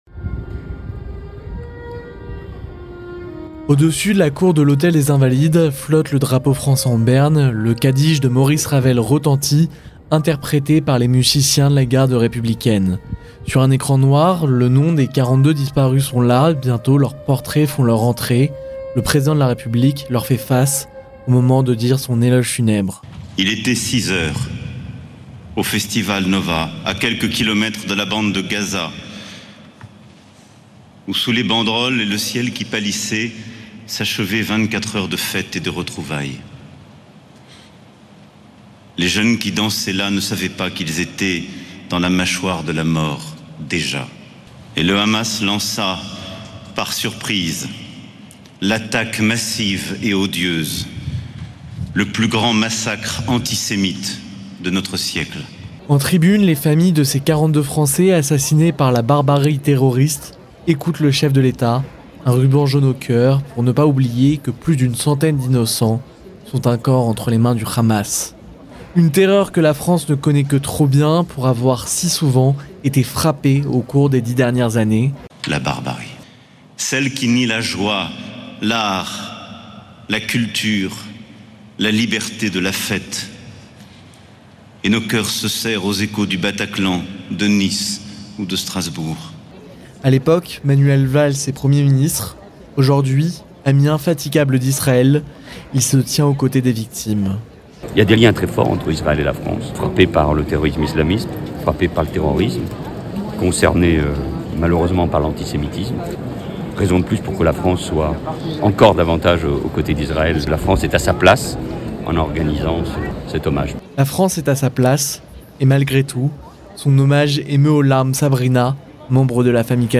La France rend hommage aux victimes françaises des attaques du 7 octobre. Reportage